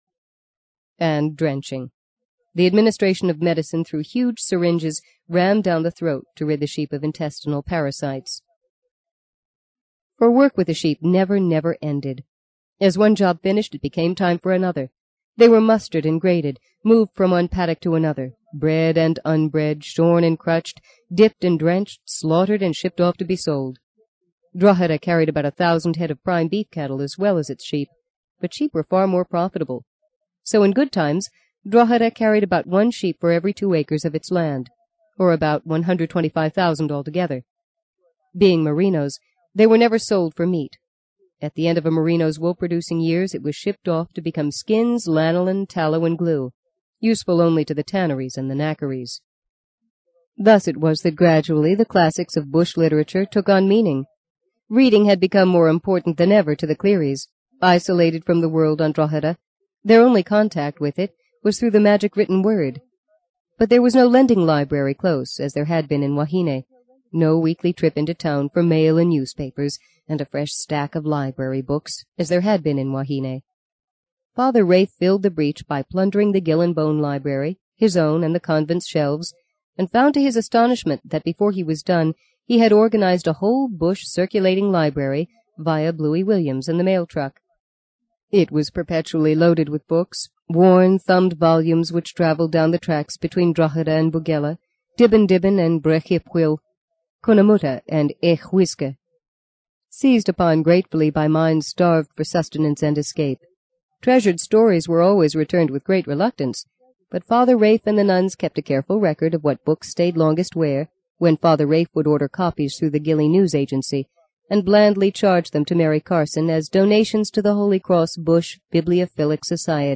在线英语听力室【荆棘鸟】第六章 16的听力文件下载,荆棘鸟—双语有声读物—听力教程—英语听力—在线英语听力室